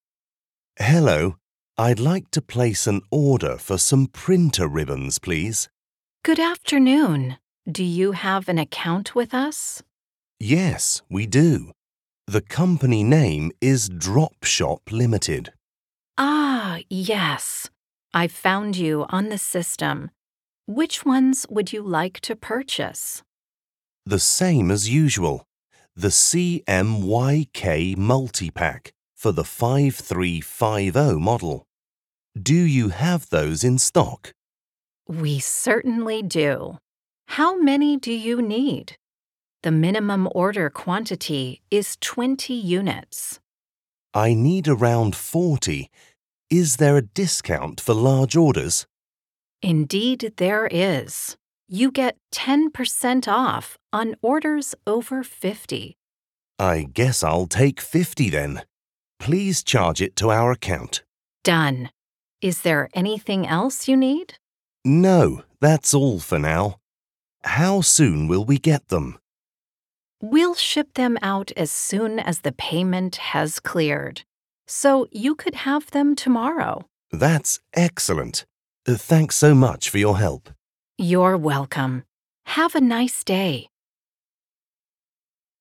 Un dialogo pratico tra un fornitore e un cliente per imparare il vocabolario e le frasi più comuni utilizzate nelle transazioni commerciali.
Speaker (UK accent)
Speaker (American accent)